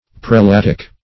Search Result for " prelatic" : The Collaborative International Dictionary of English v.0.48: Prelatic \Pre*lat"ic\, Prelatical \Pre*lat"ic*al\, a. Of or pertaining to prelates or prelacy; as, prelatical authority.
prelatic.mp3